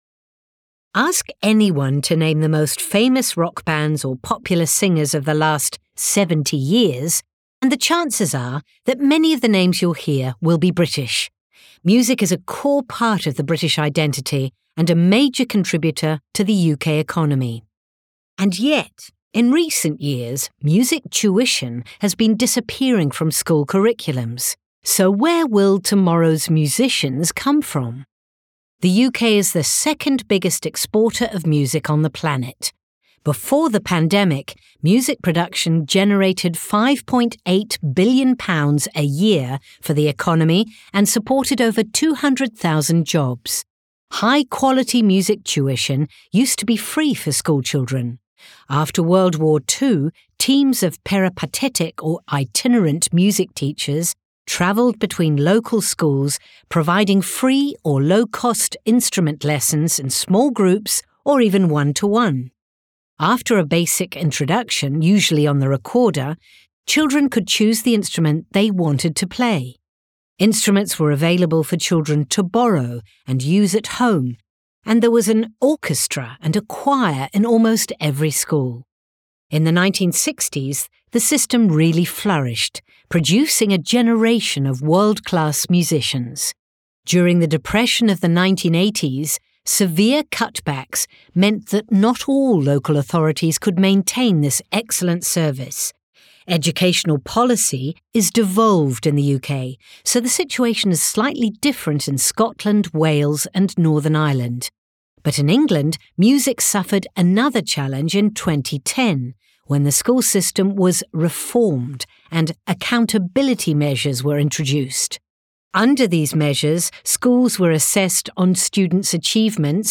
Speaker (UK accent)